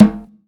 TC3Perc3.wav